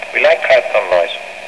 And, of course, an actor who knows how to use his voice as well as Gary Raymond can make even the oddest line sophisticated and memorable.